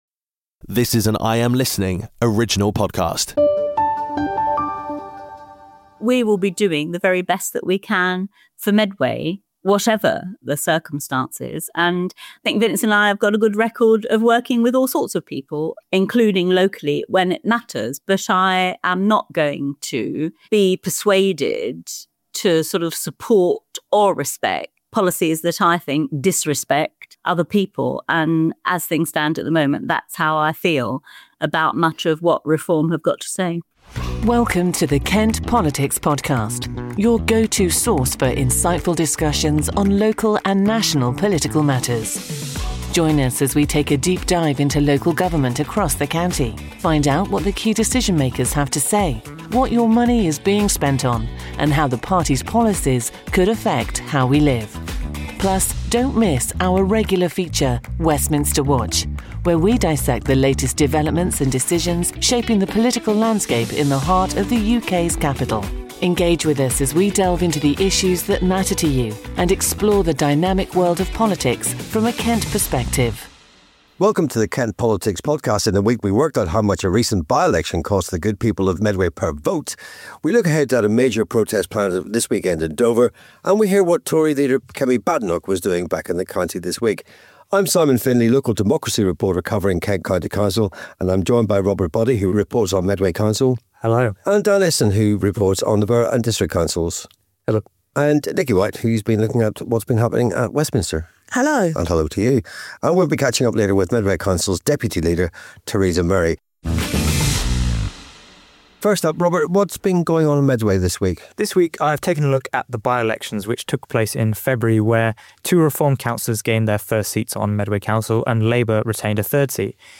Special Guest Interview: Teresa Murray This week’s guest is Councillor Teresa Murray: Background & Political Journey: From trade union activism to becoming Deputy Leader of Medway Council after Labour’s victory in May 2023.